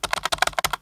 keyboard1.ogg